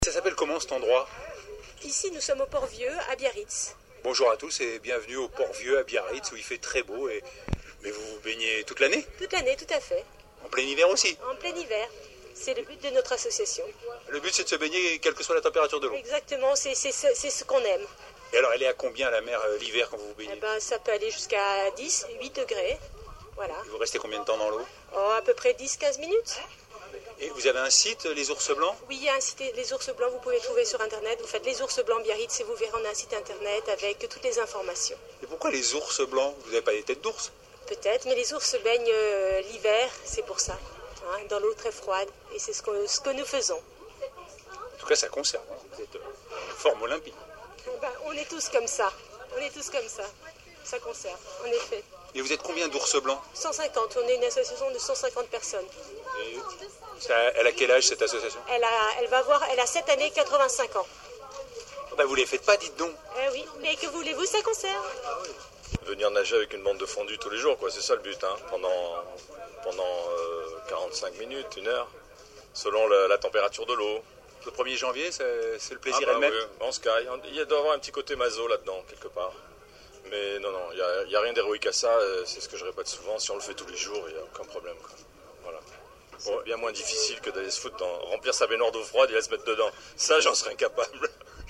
Voici un écho de ces baigneurs acharnés, cette fois-ci sur la côte basque, entendus il y a quelques jours à la radio.